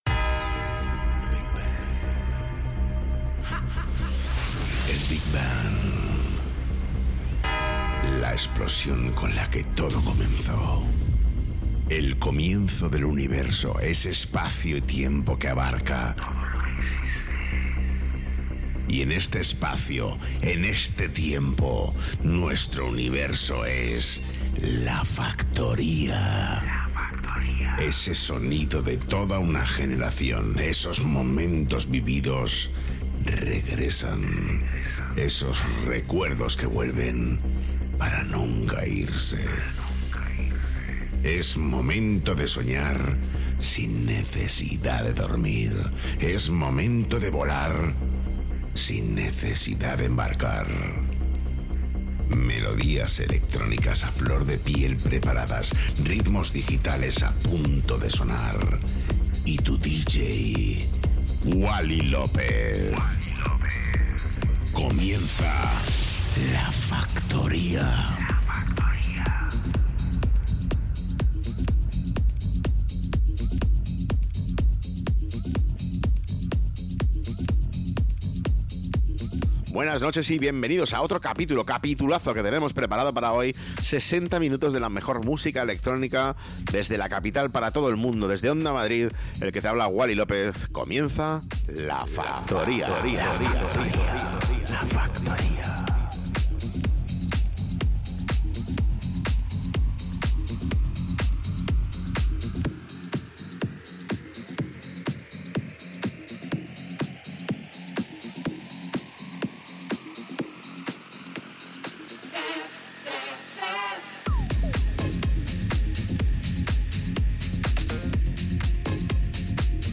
Wally López, el DJ más internacional de Madrid retoma La Factoría para todos los madrileños a través de Onda Madrid.